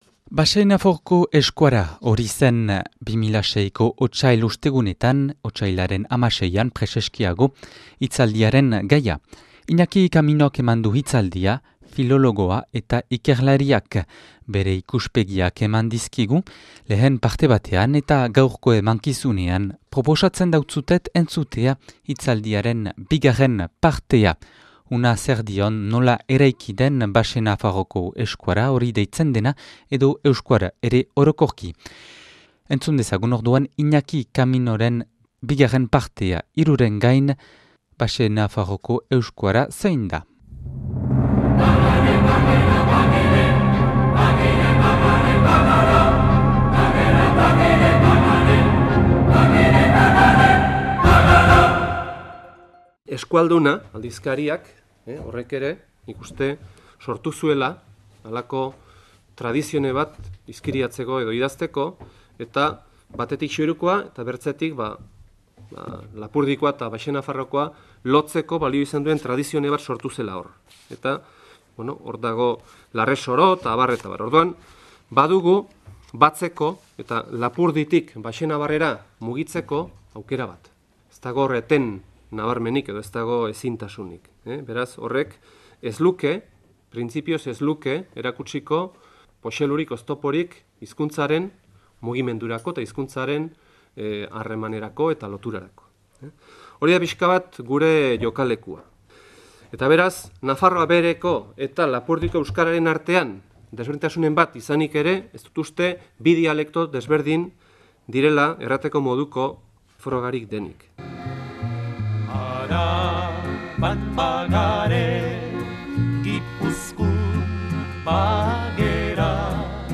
(2006. Otsailaren 16an grabatua Otsail Ostegunetan Donapaleun)